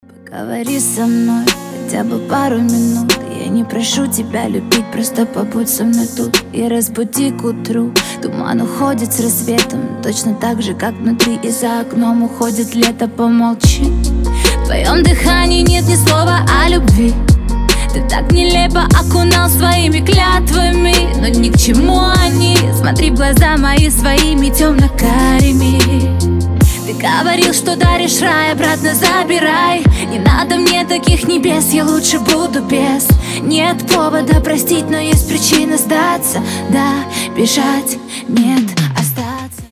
• Качество: 320, Stereo
поп
лирика
Хип-хоп